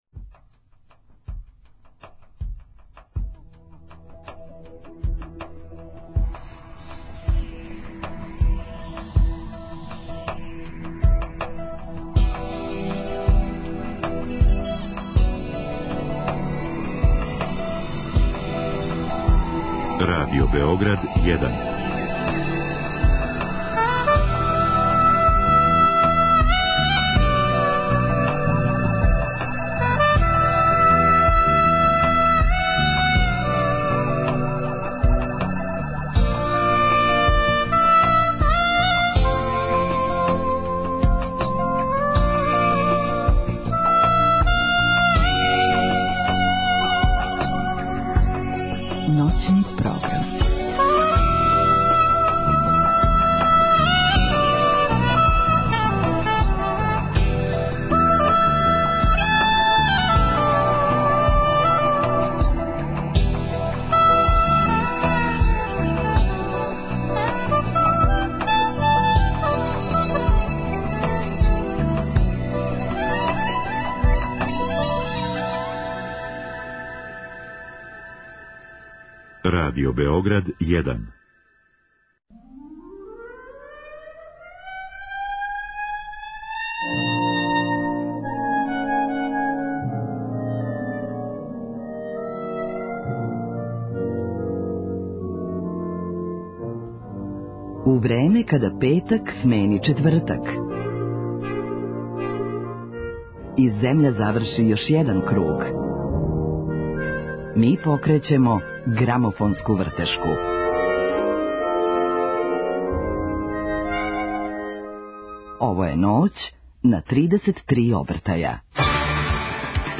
У другом сату ћемо чути фаворите овогодишње Евровизије, а коментаре и предвиђања о пласману даваће наш гост.